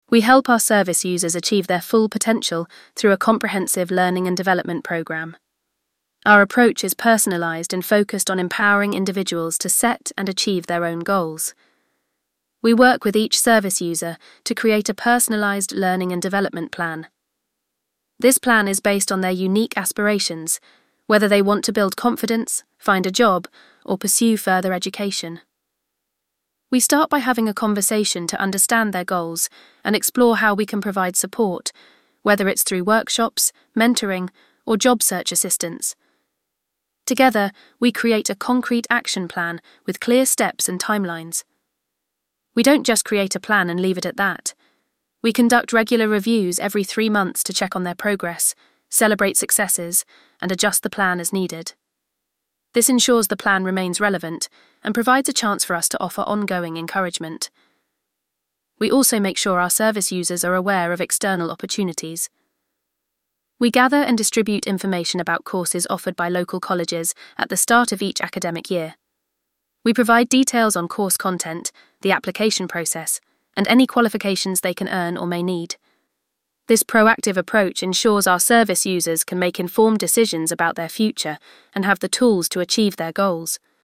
VOICEOVER-Training-Development.mp3